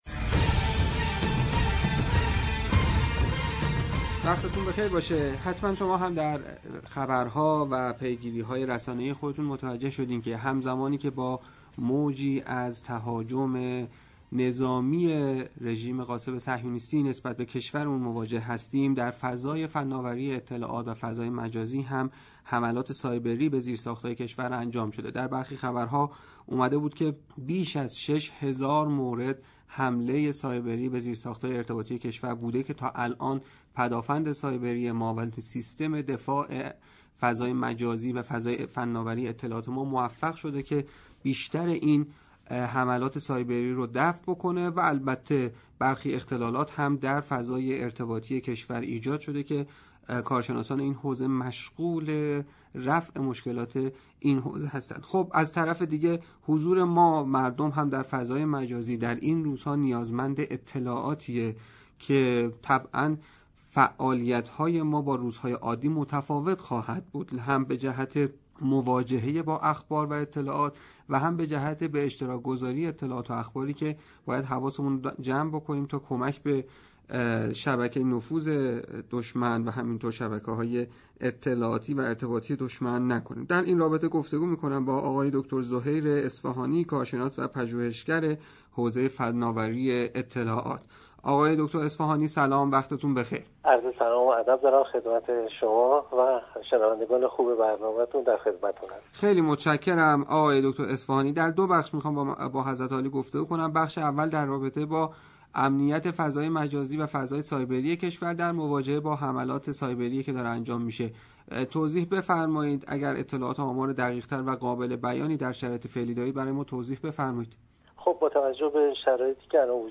كارشناس و پژوهشگر حوزه فنآوری اطلاعات